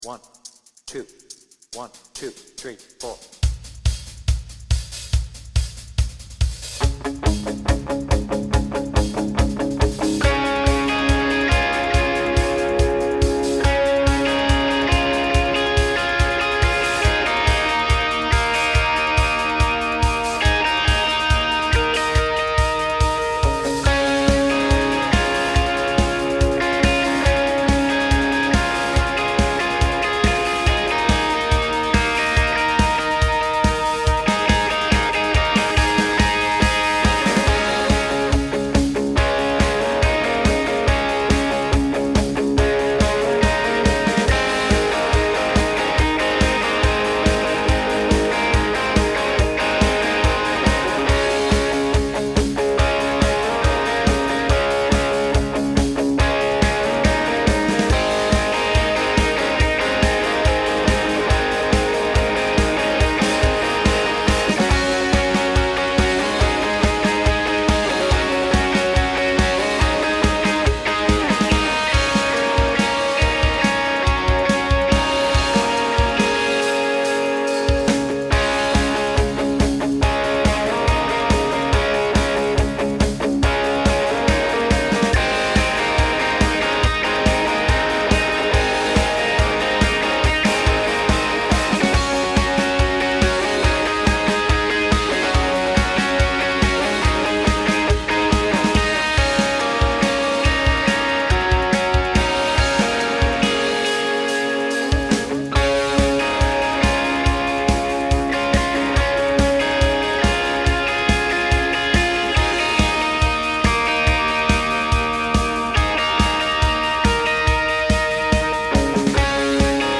Without vocals
Based on the Slane Castle version